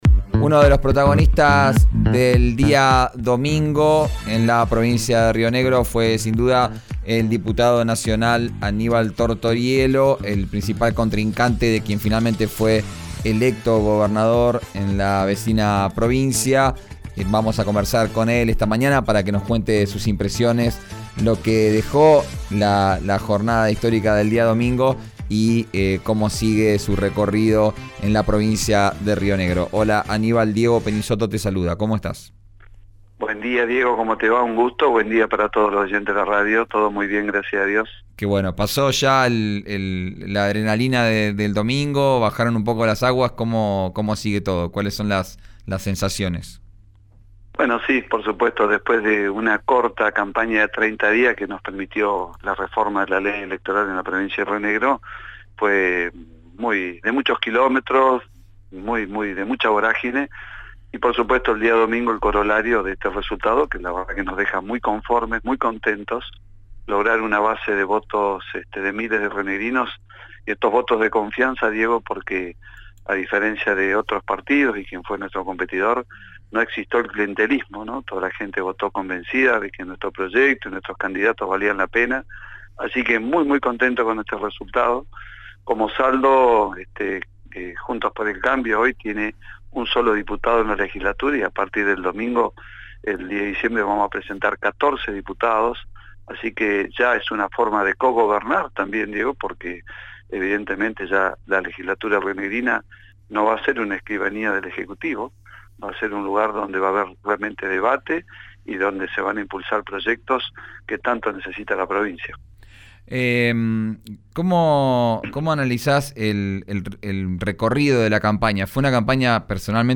Aníbal Tortoriello manifestó a RÍO NEGRO RADIO que, después de la corta campaña que tuvieron los partidos por la reforma de la ley electoral de la provincia de Río Negro, los resultados los dejan muy conformes, ya que Juntos por el Cambio logró una base de votos de miles rionegrinos que los apoyaran en las elecciones nacionales de agosto y octubre.